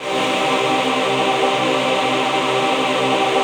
VOICEPAD17-LR.wav